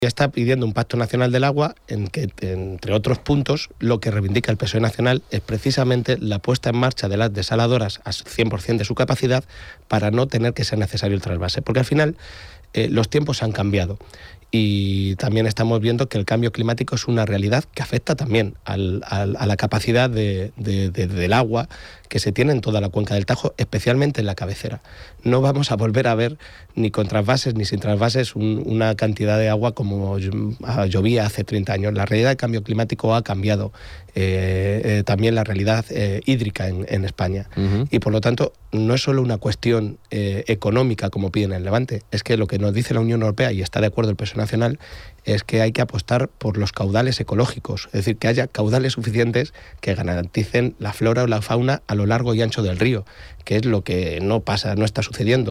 En una entrevista en Onda Cero Castilla-La Mancha
Cortes de audio de la rueda de prensa